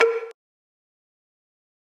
OZ-Perc (Outside).wav